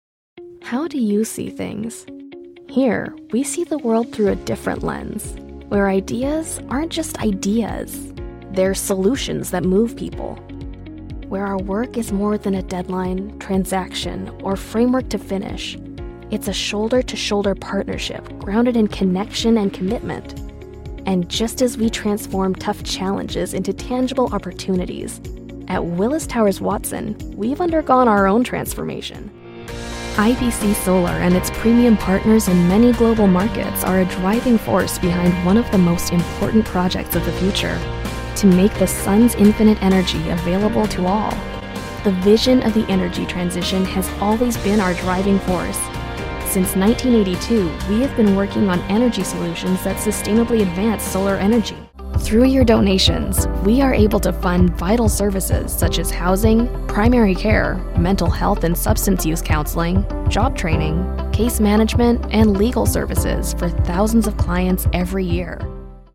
Comercial, Natural, Travieso, Versátil, Amable
Corporativo